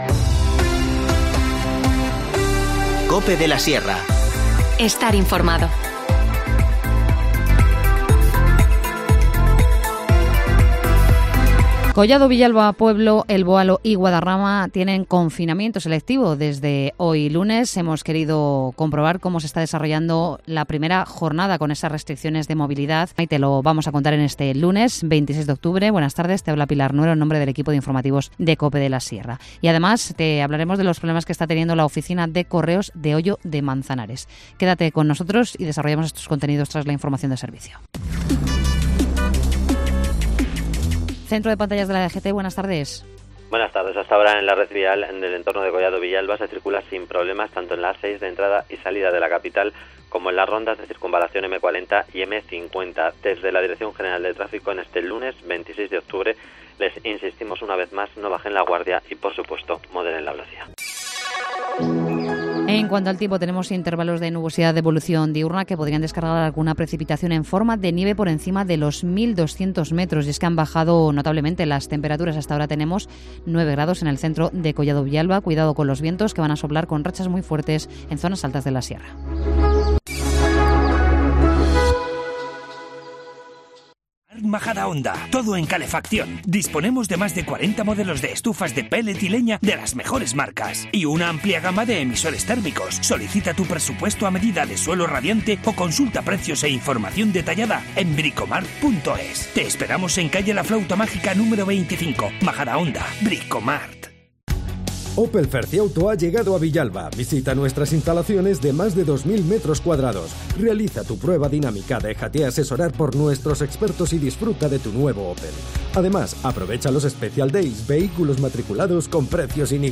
Informativo Mediodía 26 octubre